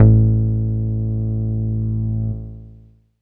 VEC1 Bass Long 02 A.wav